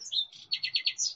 SFX_Bird_3.ogg